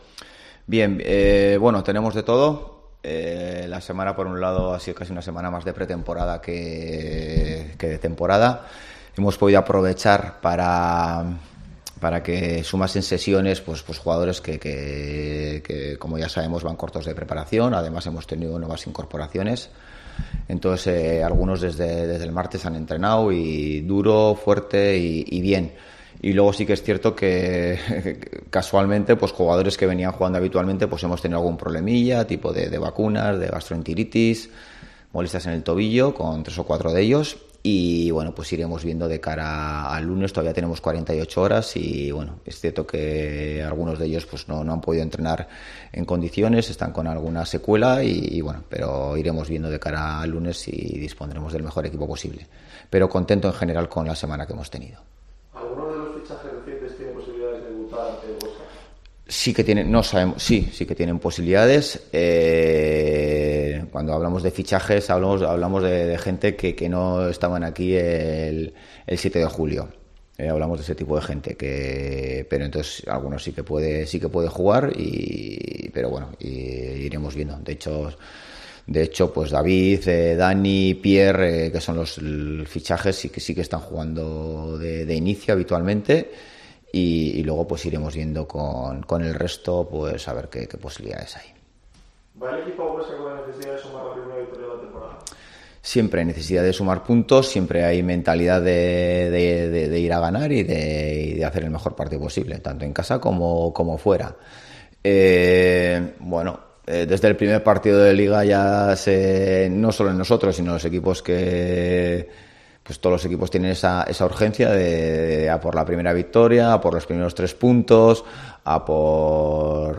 Rueda de prensa Ziganda (previa Huesca-Oviedo)